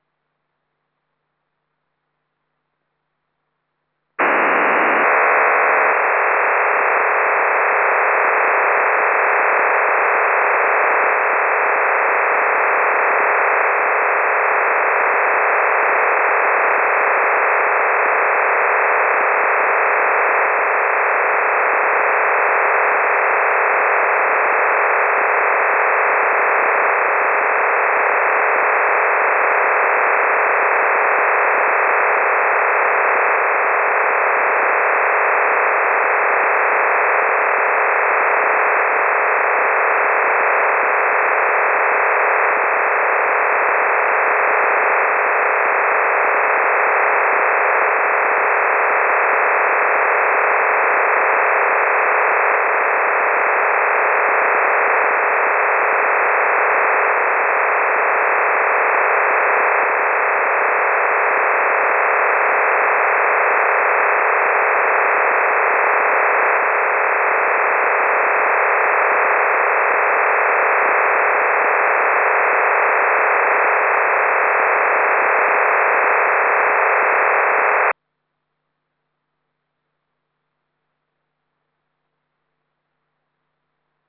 OFDM - NRB - приемник в РРУ
Приемник ICOM IC-R9000L.
Есть небольшое смещение по частоте, но ничего фатального.
Профессиональный передатчик 1 кВт работает с отключенной АРУ по входу возбудительного устройства, небольшие искажения вносит только АРН усилителя мощности.
icom_ic_r9000l_agc_off.wav